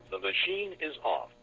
描述：答录机说："机器已关闭".
Tag: 电话应答MACINE